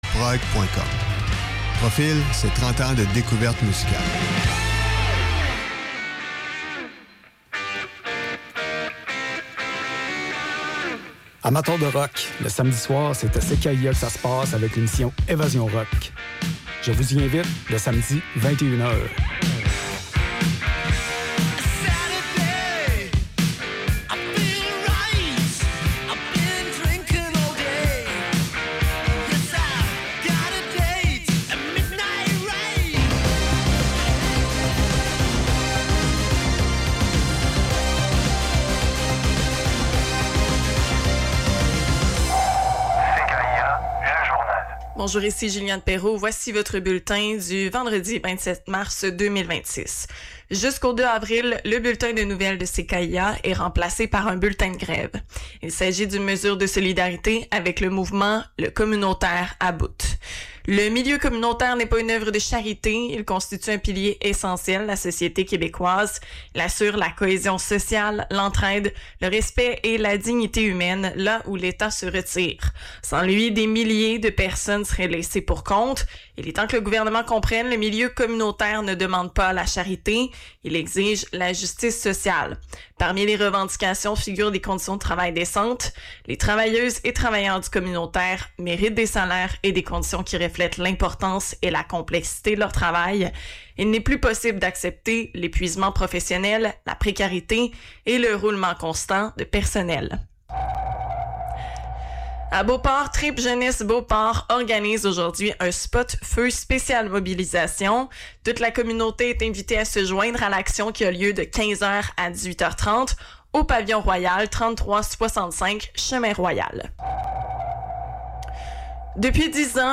Revue de presse liée à l’actualité, aux changements climatiques et à la surconsommation, principalement dans Le Devoir.